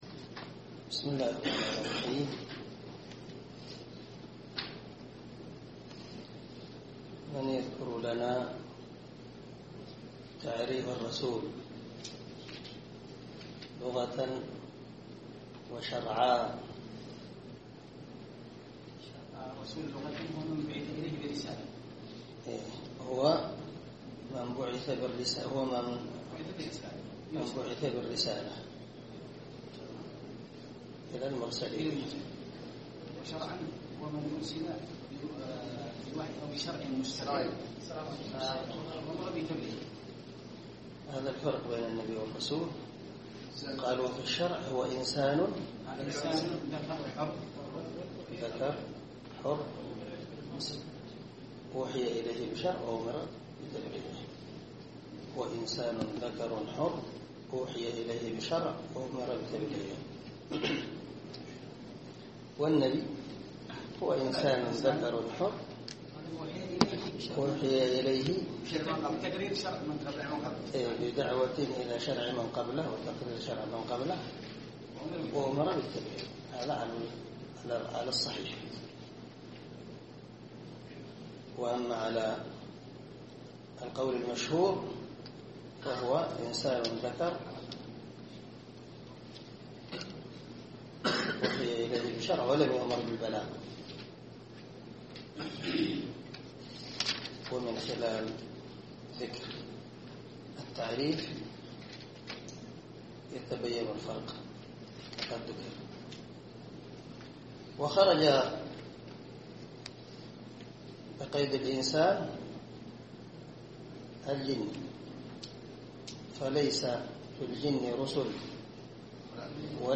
عنوان الدرس: الدرس الخامس
دار الحديث- المَحاوِلة- الصبيحة.